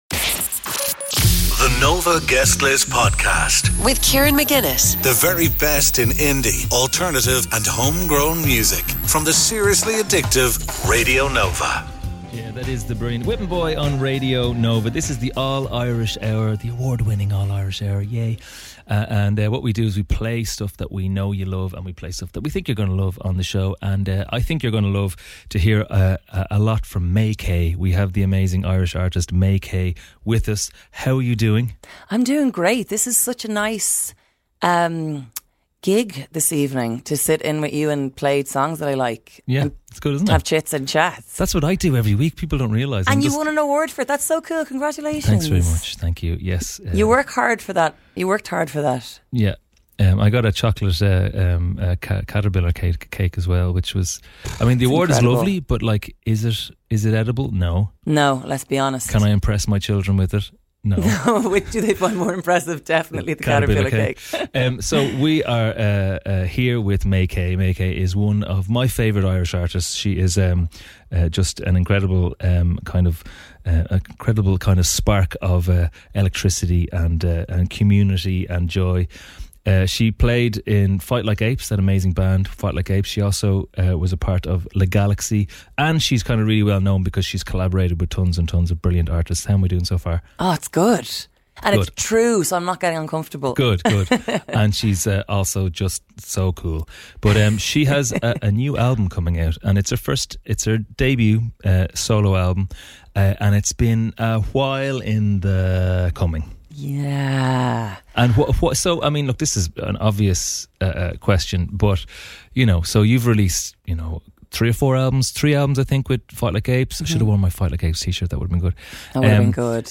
The podcast brings unique interviews with